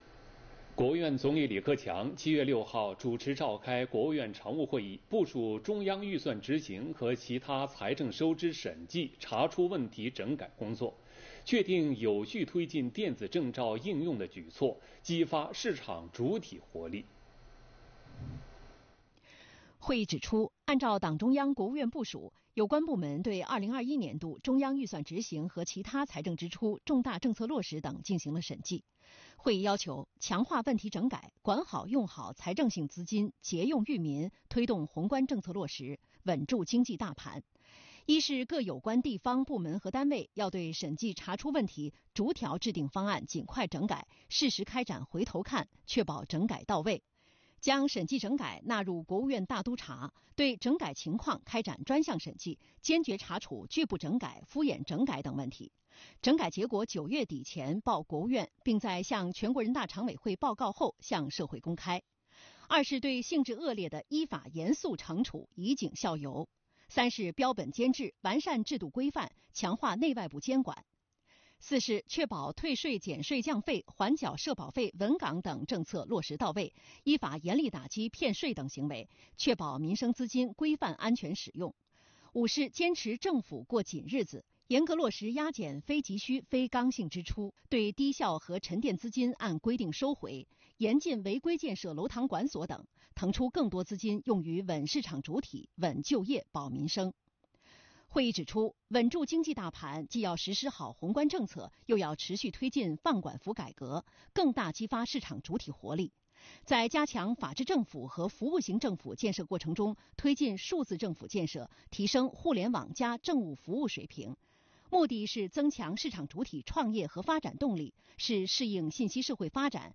李克强主持召开国务院常务会议